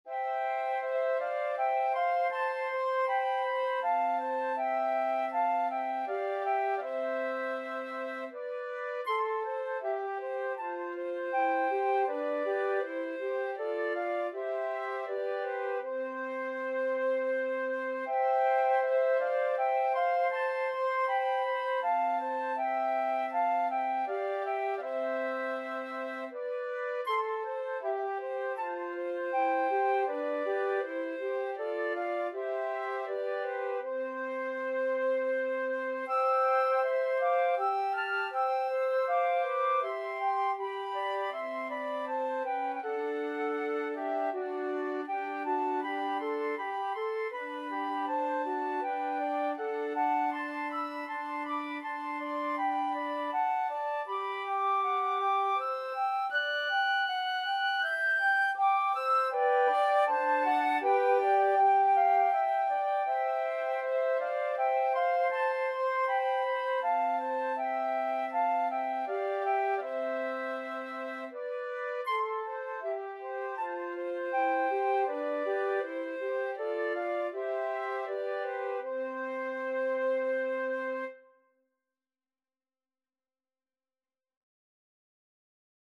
Free Sheet music for Flute Trio
C major (Sounding Pitch) (View more C major Music for Flute Trio )
3/4 (View more 3/4 Music)
Andante
Classical (View more Classical Flute Trio Music)